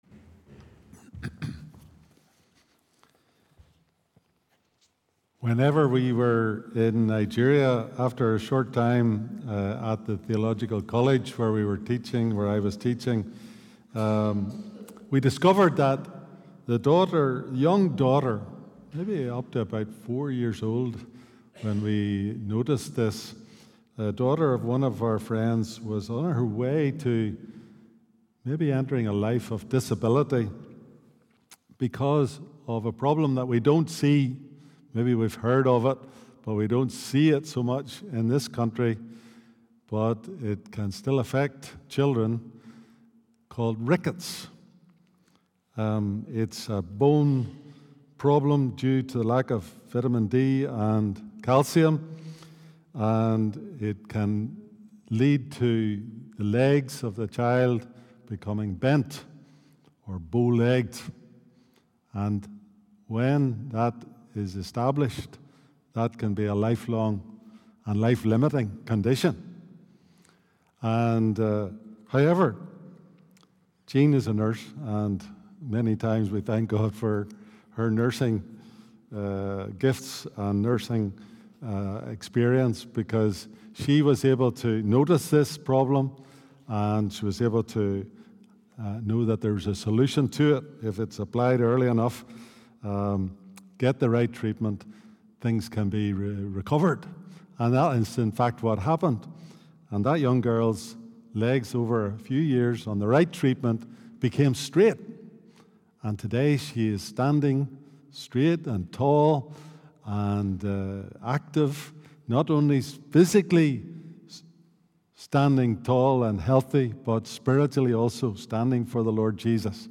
A Sermon from the series "Standalone Sermons."